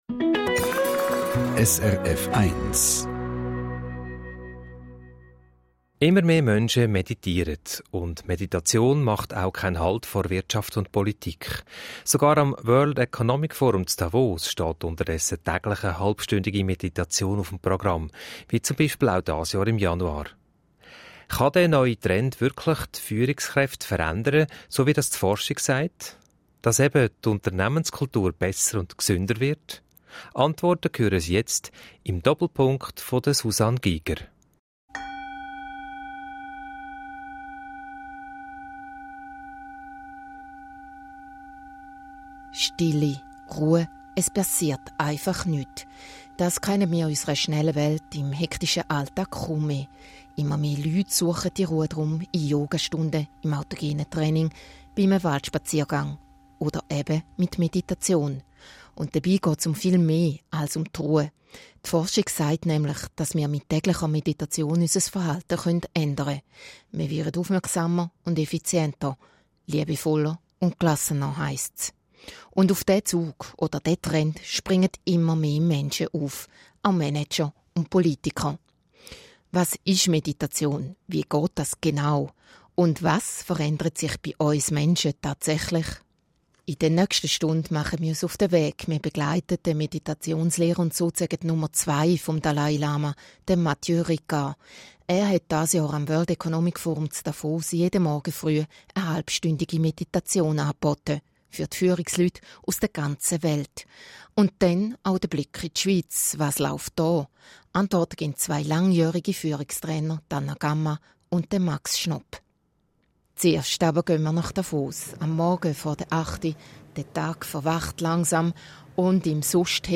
Reportage über Mathieu Ricard